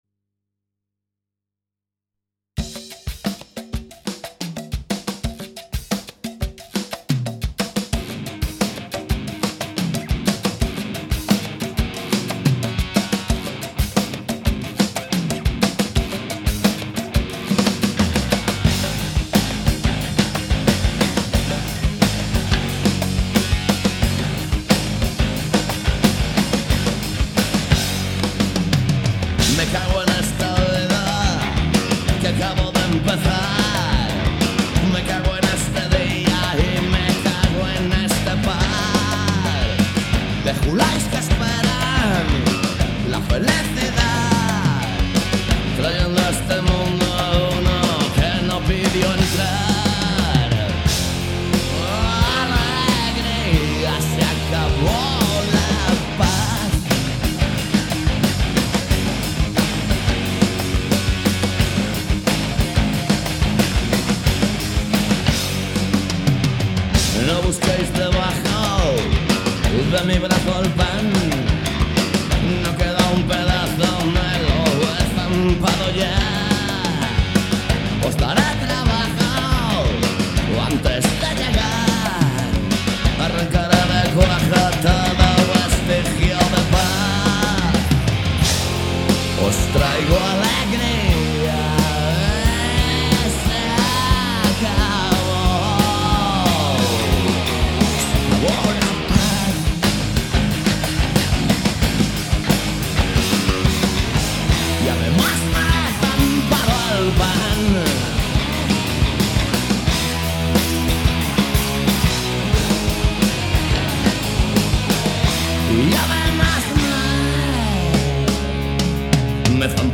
Todo iso misturado con boa música e un pouco de humor se o tema o permite. Cada martes ás 18 horas en directo.
Alegría comezou a emitirse en outubro de 2003 e dende entón non falla nas ondas de CUAC FM.